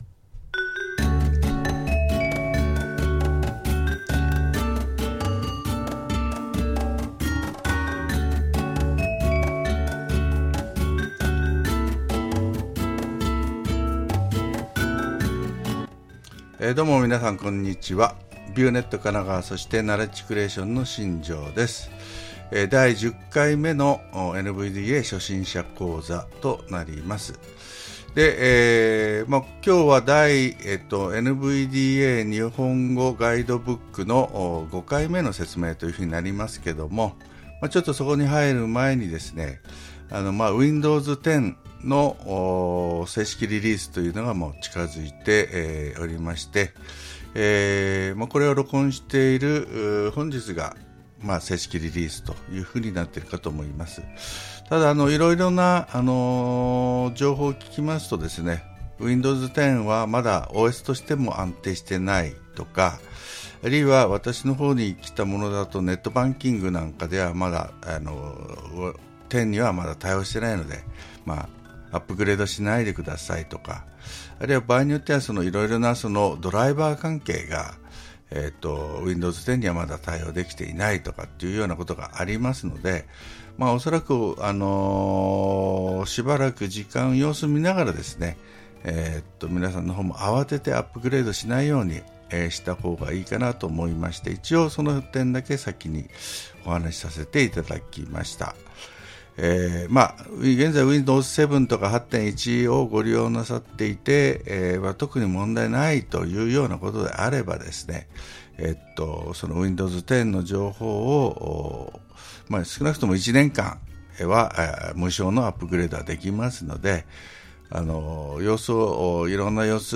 ＮＶＤＡのSkype初心者講座は、「NVDA 日本語版ガイドブック 第4版」 2014年12月25日」を元に講座を進めていますがこの内容にしたがって実際にＮＶＤＡを操作しながら補足説明を加えています。
なお、まだ録音に慣れていないためお聞き苦しいところは多くあるとは思いますが、ご容赦ください。